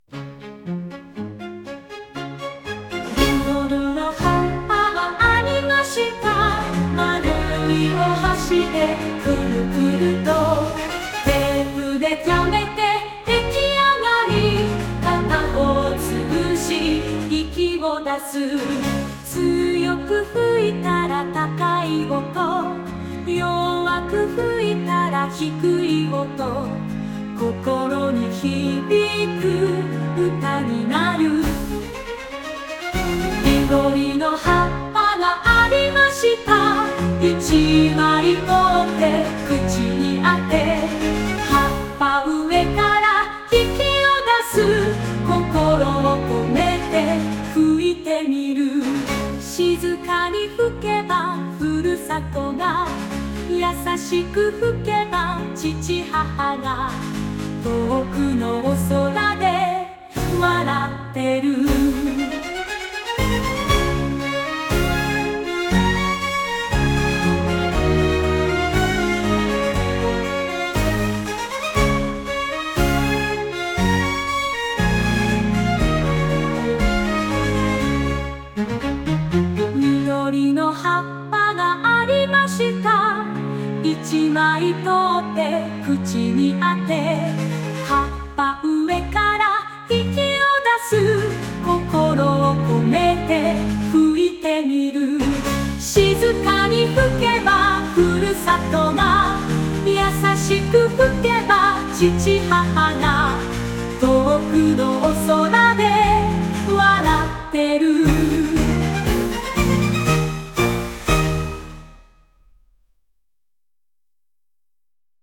midori-no-hue-b.mp3